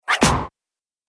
دانلود آهنگ تصادف 22 از افکت صوتی حمل و نقل
جلوه های صوتی
دانلود صدای تصادف 22 از ساعد نیوز با لینک مستقیم و کیفیت بالا